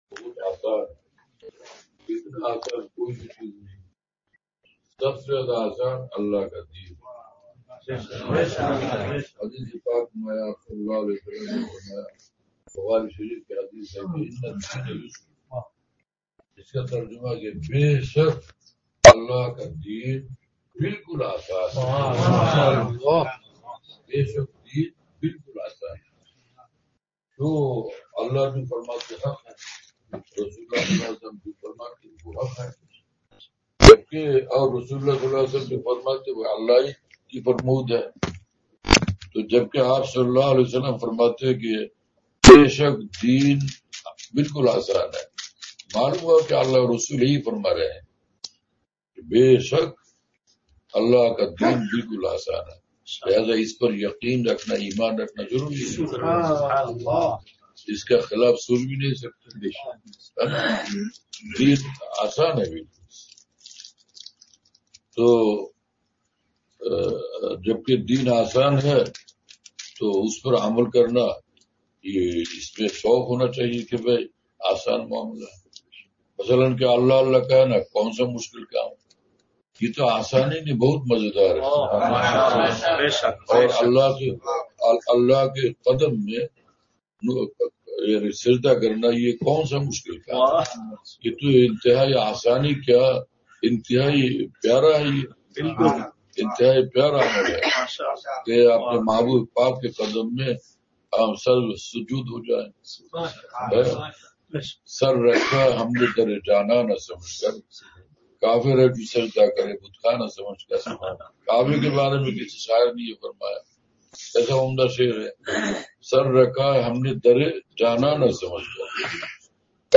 مجلس